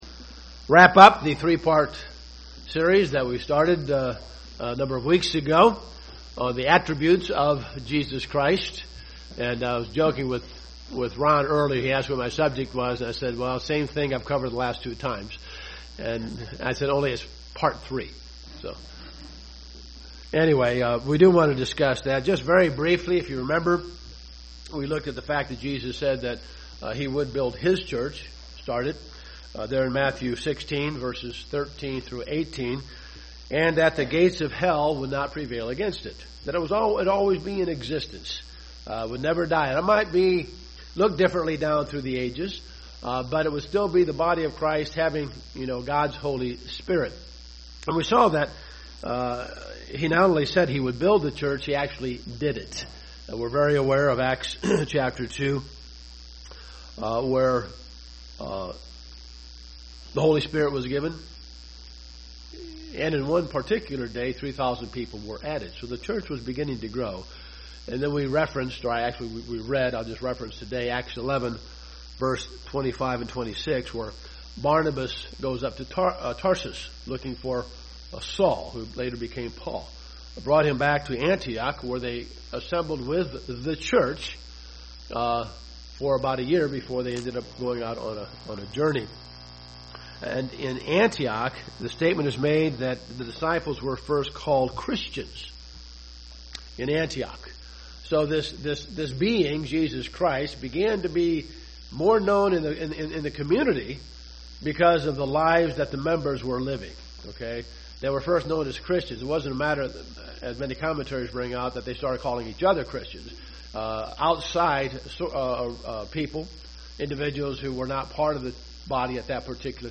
This sermon is the last in the series depicting the attributes of Jesus Christ